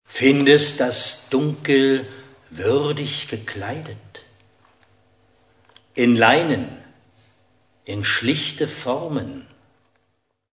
Die mit "Audioclip" gekennzeichneten mp3-Stücke enthalten kleine Ausschnitte aus dem literarisch-musikalischen Programm